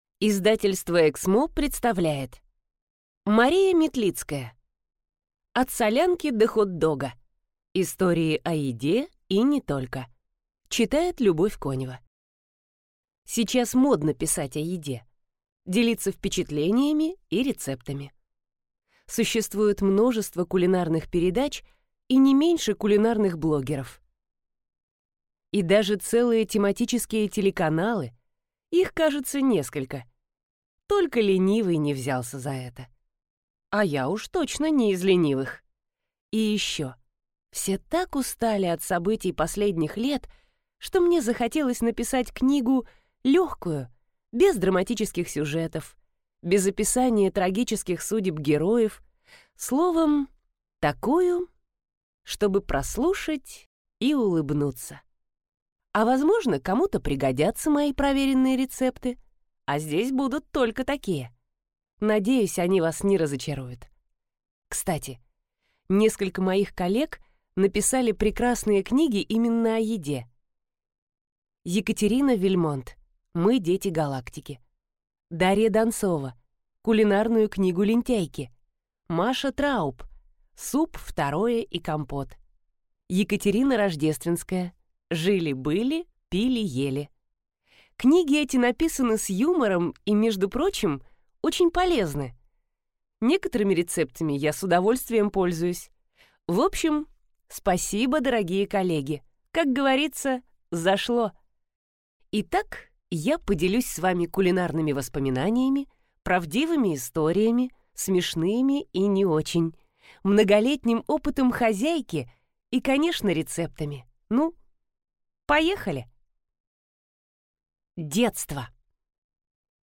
Аудиокнига От солянки до хот-дога. Истории о еде и не только | Библиотека аудиокниг